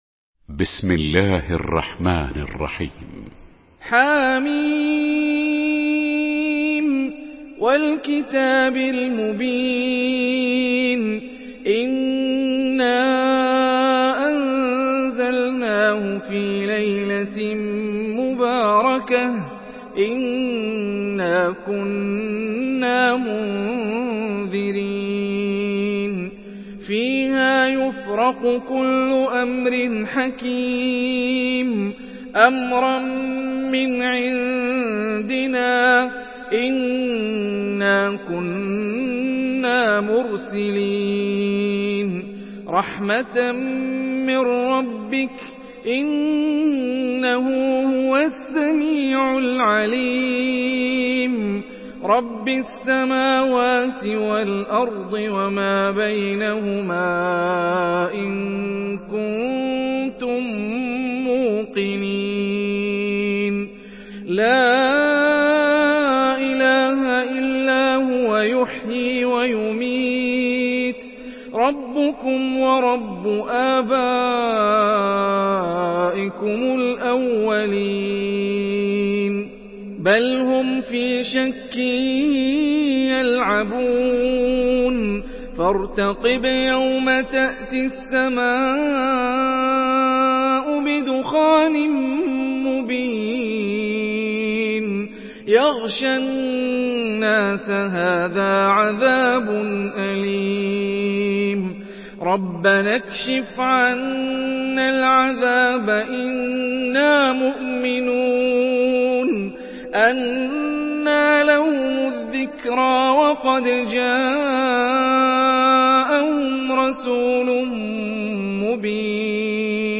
Surah Ad-Dukhan MP3 by Hani Rifai in Hafs An Asim narration.
Murattal Hafs An Asim